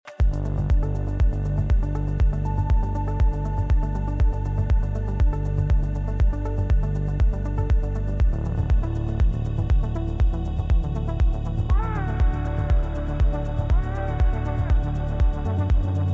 Falling-sus-bass.mp3